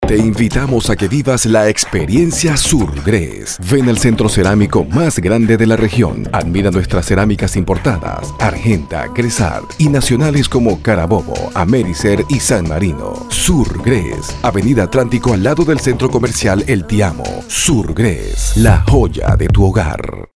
Kein Dialekt
Sprechprobe: Werbung (Muttersprache):
Voice over, professional speaker for more than 20 years, own recording studio and availability 24/7. Commercial, IVR, audio books, corporate. documentaries ...